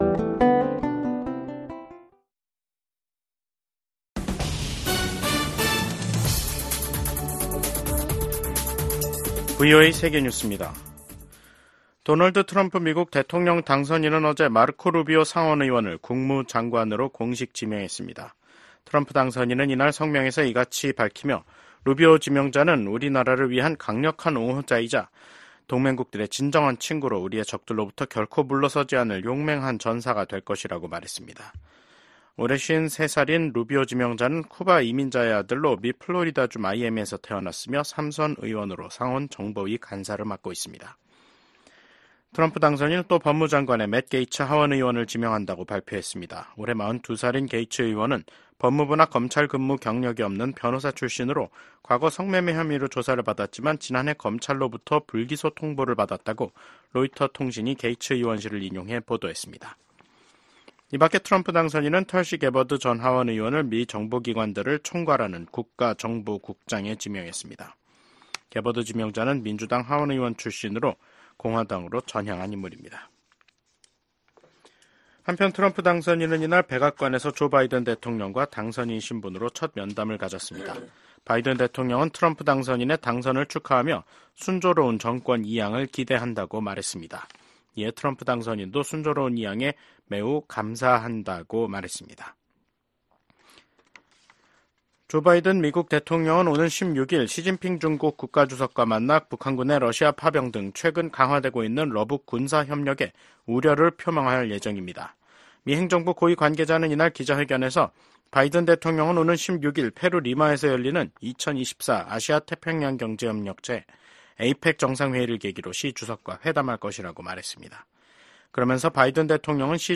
VOA 한국어 간판 뉴스 프로그램 '뉴스 투데이', 2024년 11월 13일 2부 방송입니다. 미국 백악관이 북한군의 러시아 파병을 공식 확인했습니다. 최소 3천명이 러시아 동부 전선에 파병됐으며 훈련 뒤엔 우크라이나와의 전투에 배치될 가능성이 있다고 밝혔습니다.